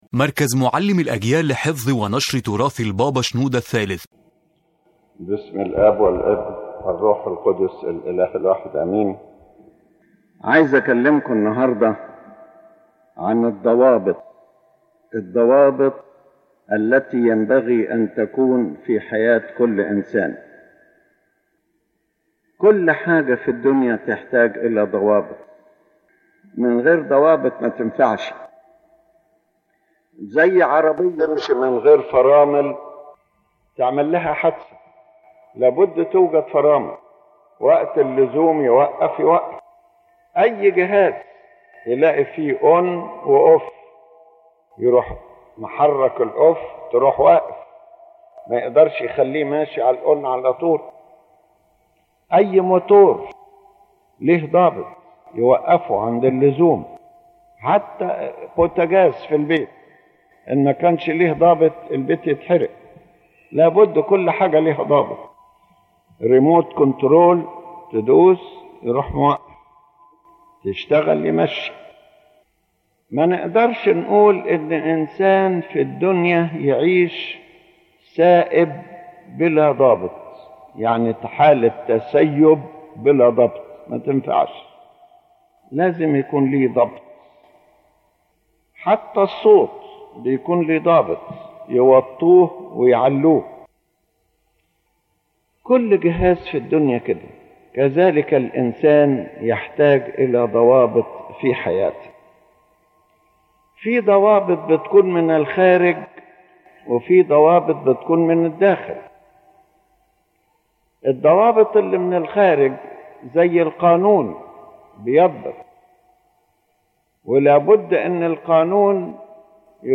يتحدث قداسة البابا شنوده الثالث في هذه المحاضرة عن أهمية الضوابط في حياة الإنسان، مؤكدًا أن كل شيء في الحياة يحتاج إلى تنظيم وضبط، لأن الحياة بدون ضوابط تؤدي إلى الفوضى والانحراف. فالإنسان، مثل أي جهاز، يحتاج إلى ما يوقفه عند الحد المناسب حتى لا يضر نفسه أو غيره.